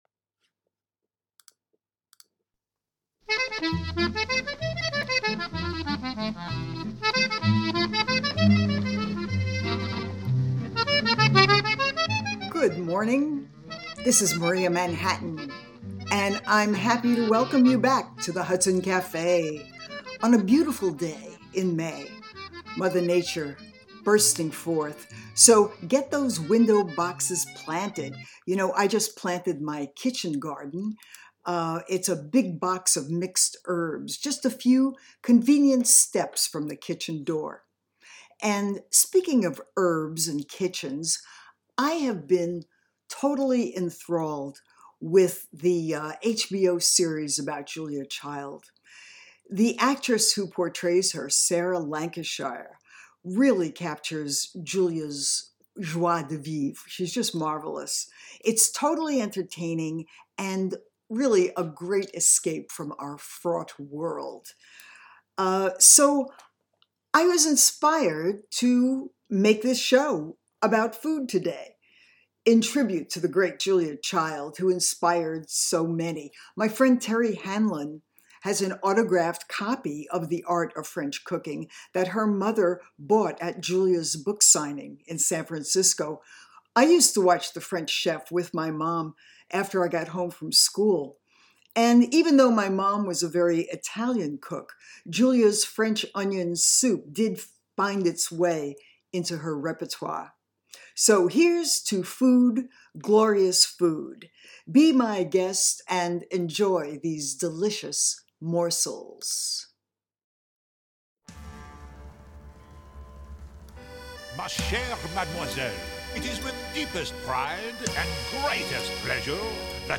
upbeat music show